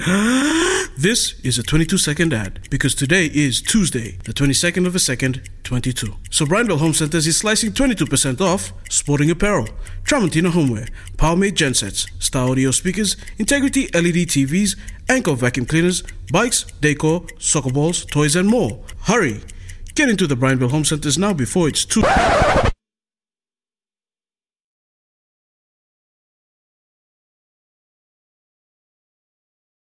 The 22-second 30-second radio ad.
So, we made and aired a 22 second radio ad.